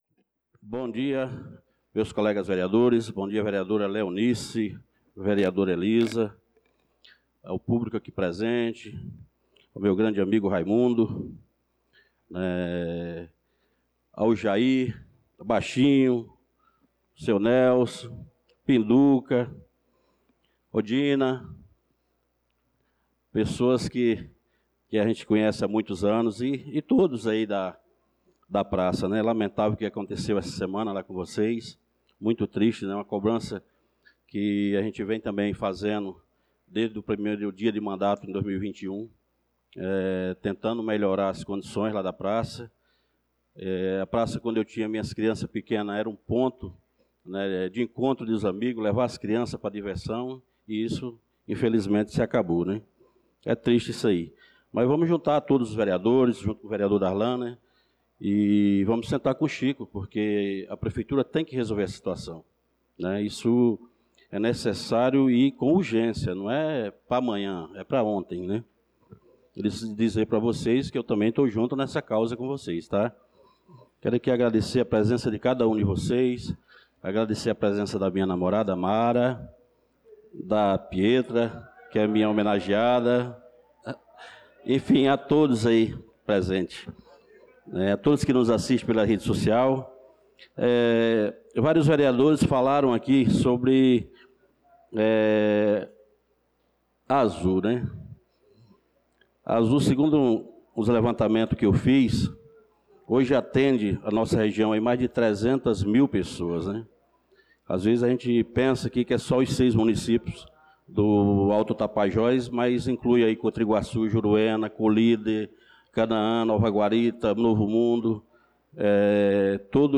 Pronunciamento do vereador Francisco Ailton na Sessão Ordinária do dia 02/06/2025